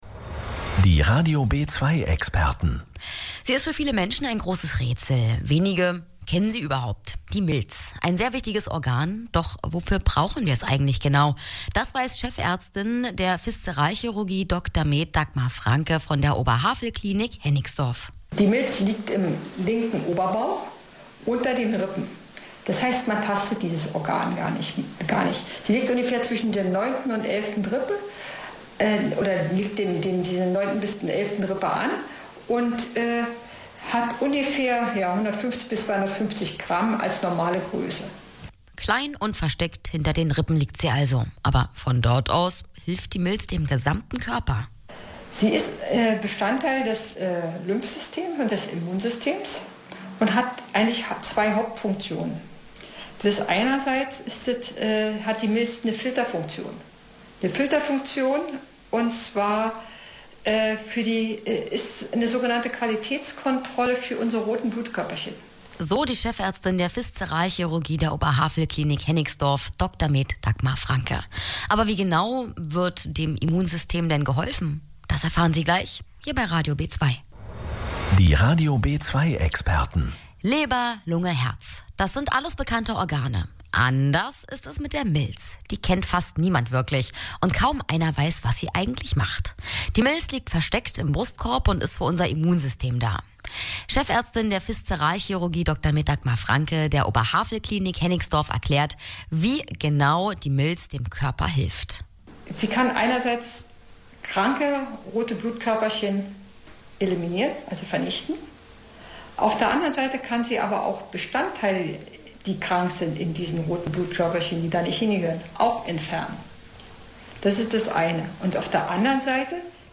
im Interview bei Radio B2.